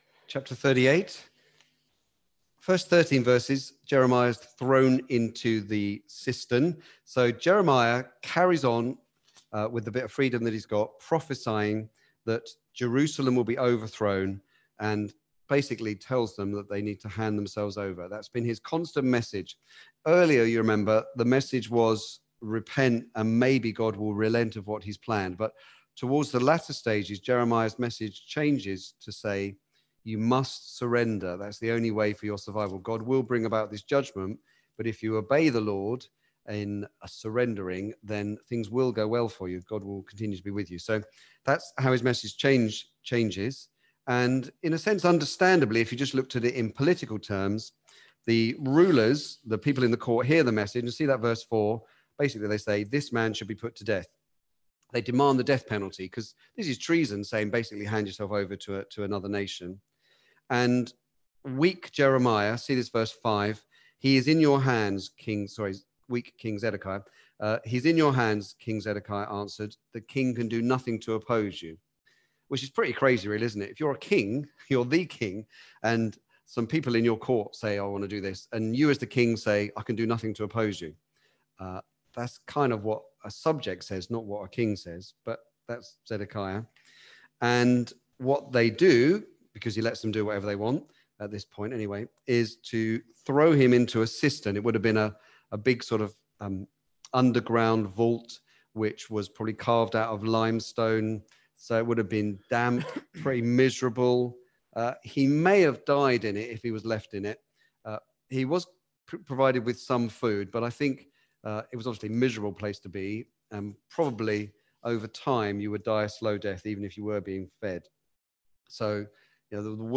Back to Sermons The word locked up but not silenced